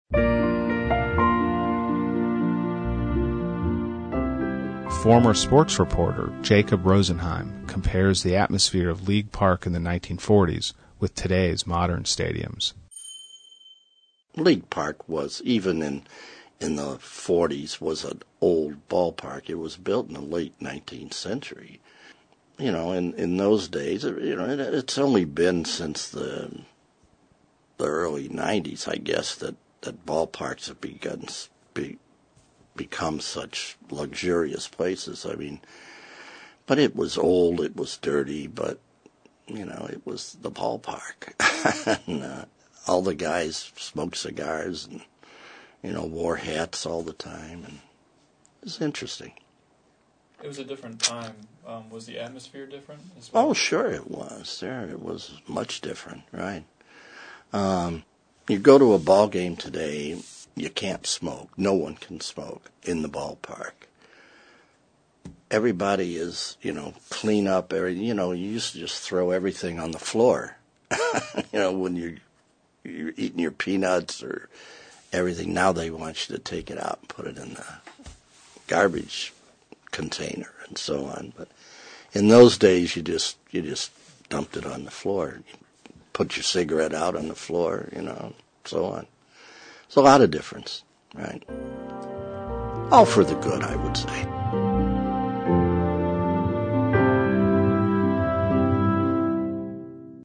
| Source: Cleveland Regional Oral History Collection